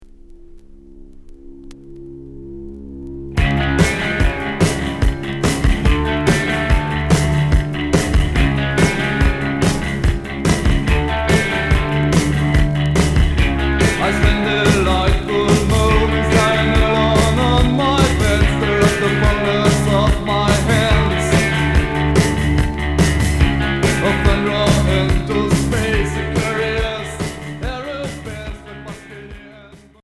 Cold wave Unique Maxi 45t